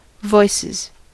Uttal
Uttal US Ordet hittades på dessa språk: engelska Ingen översättning hittades i den valda målspråket.